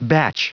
Prononciation du mot batch en anglais (fichier audio)
Prononciation du mot : batch